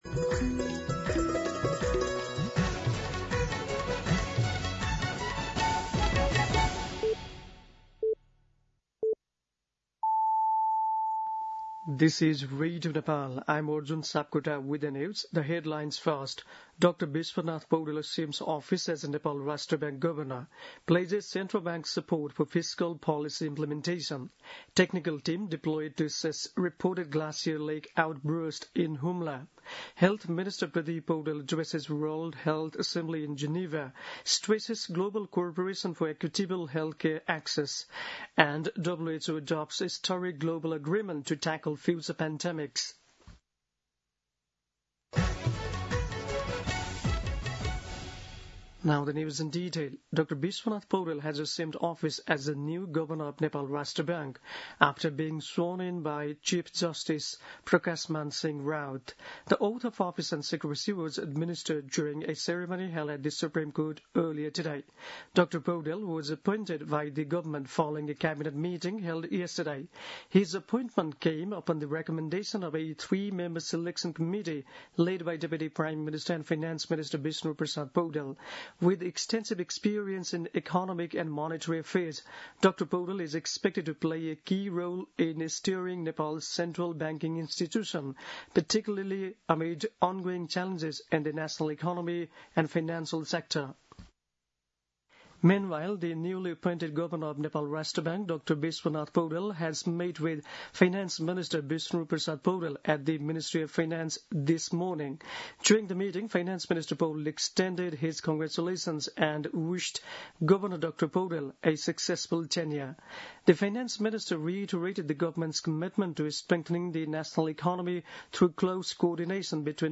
दिउँसो २ बजेको अङ्ग्रेजी समाचार : ७ जेठ , २०८२
2-pm-English-News-02-07.mp3